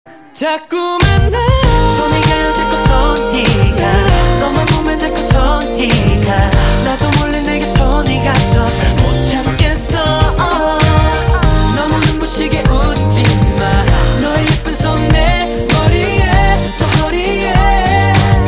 M4R铃声, MP3铃声, 日韩歌曲 96 首发日期：2018-05-15 07:33 星期二